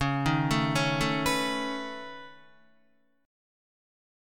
Db7sus2#5 chord